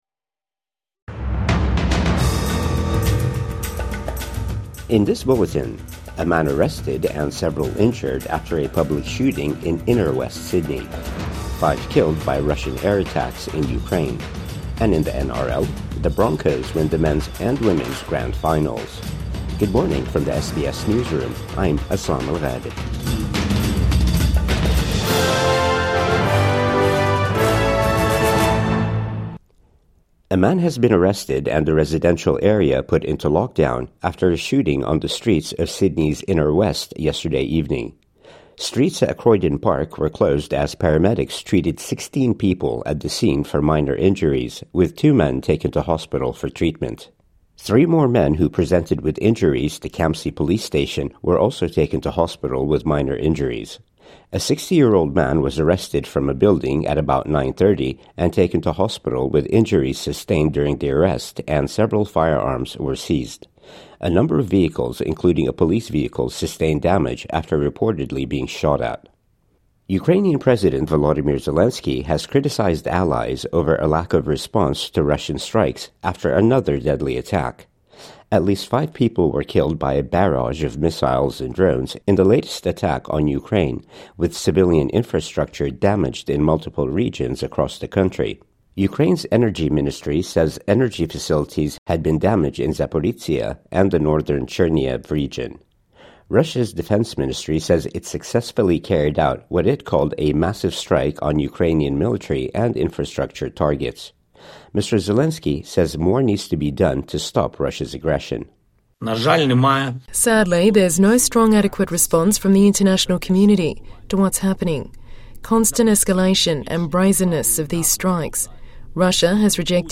Man arrested over Sydney shooting | Morning News Bulletin 6 October 2025